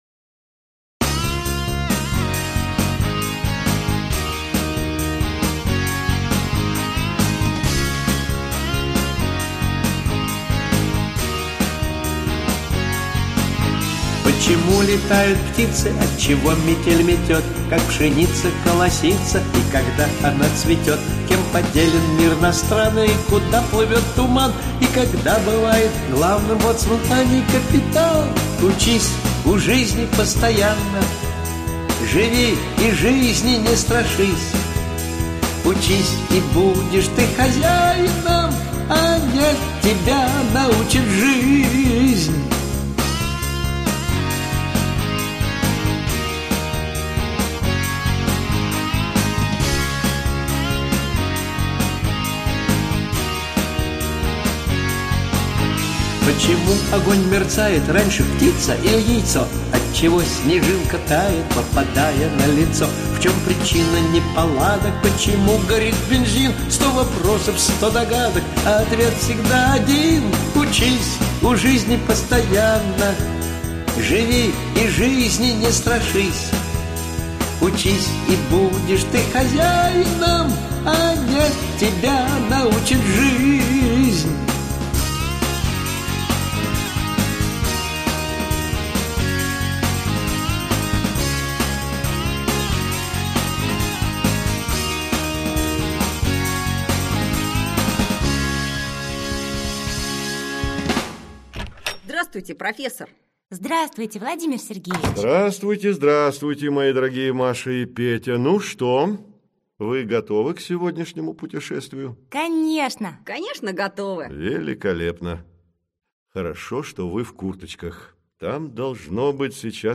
Аудиокнига Транспорт: Метрополитен | Библиотека аудиокниг